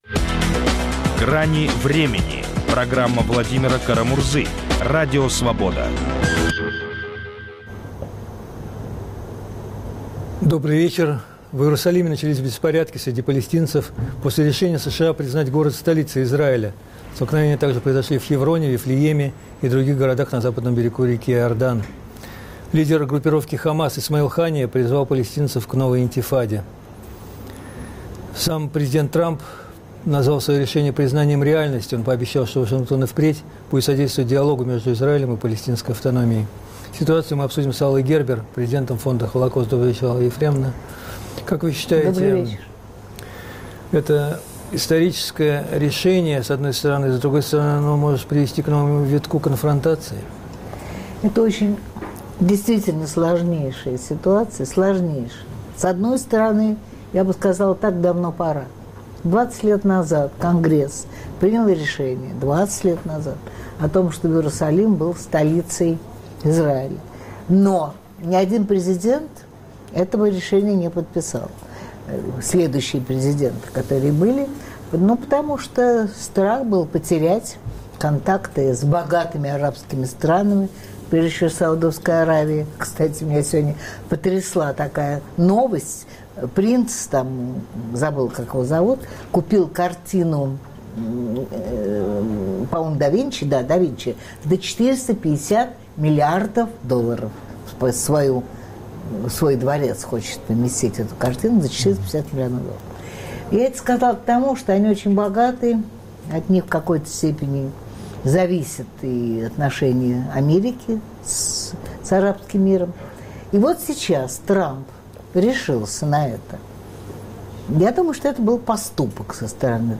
Ведущий - Владимир Кара - Мурза - старший.